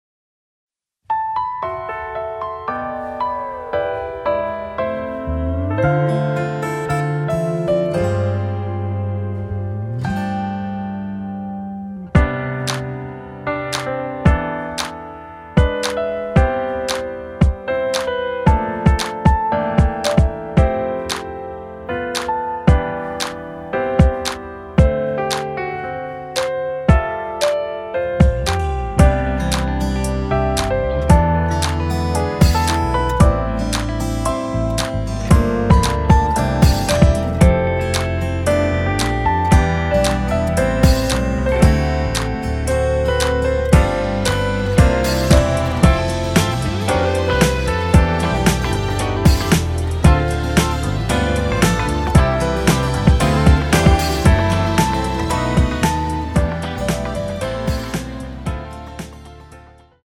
” [공식 음원 MR] 입니다.
앞부분30초, 뒷부분30초씩 편집해서 올려 드리고 있습니다.
중간에 음이 끈어지고 다시 나오는 이유는
위처럼 미리듣기를 만들어서 그렇습니다.